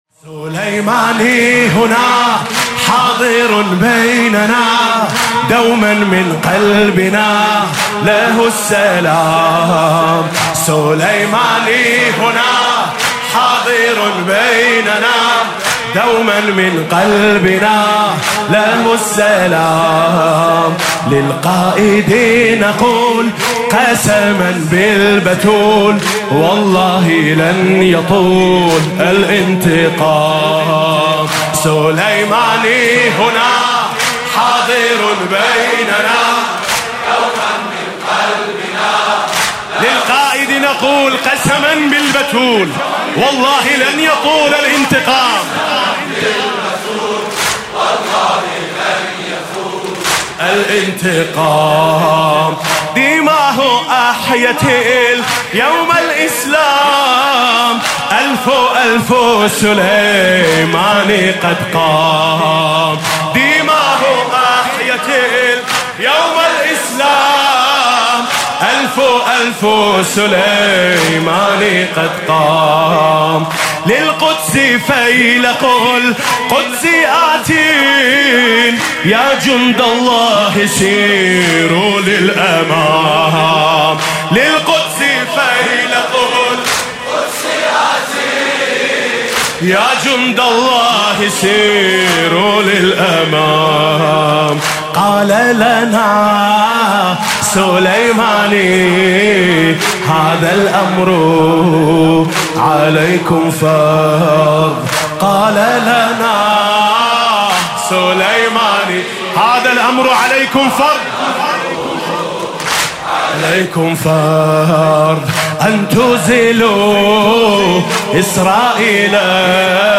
فاطمیه اول 98 - شب دوم - واحد عربی - ألفُ ألفُ سُلیمانیْ قدْ قَامْ